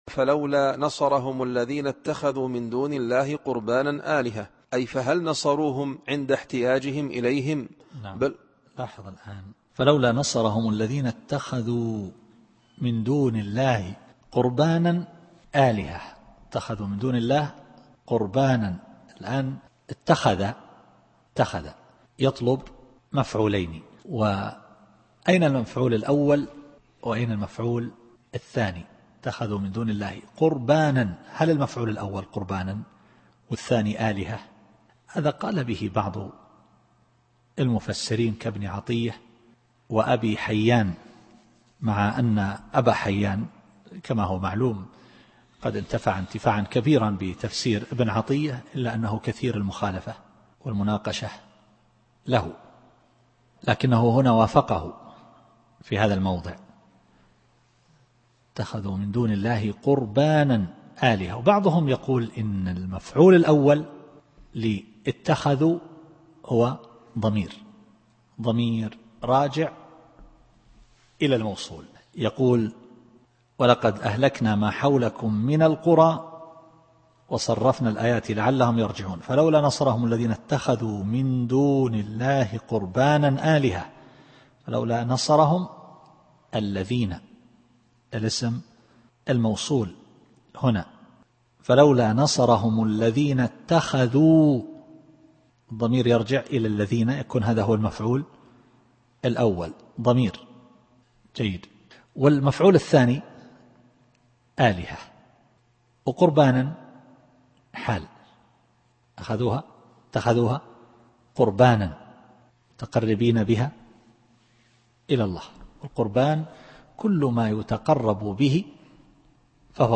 التفسير الصوتي [الأحقاف / 28]